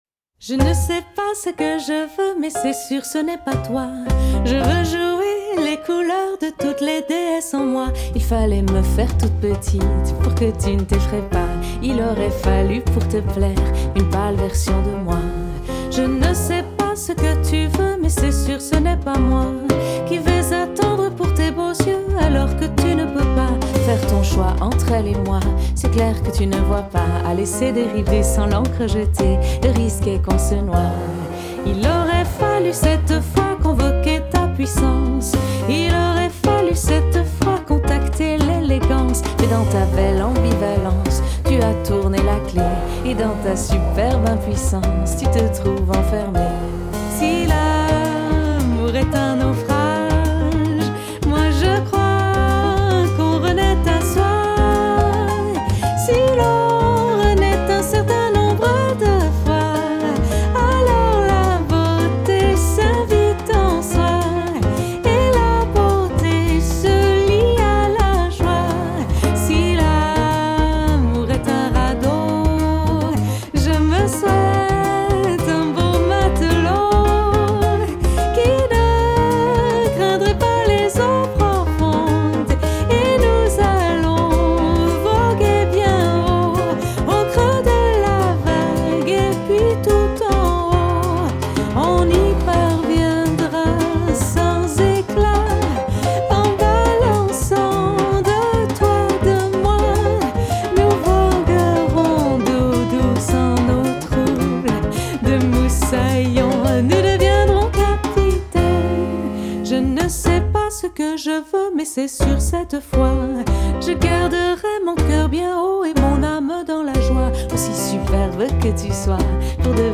coloré, optimiste et amoureux.